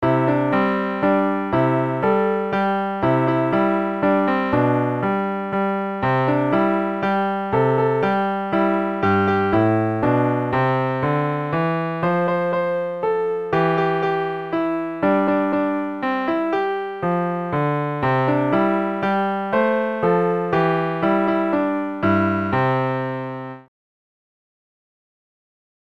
童謡・唱歌：『こいのぼり』